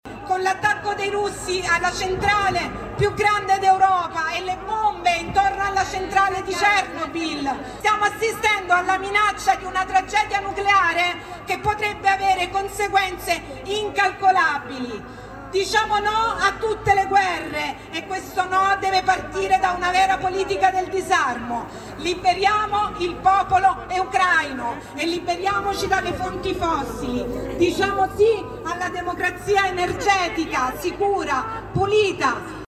Tra i temi affrontati dal palco di piazza San Giovanni la crisi energetica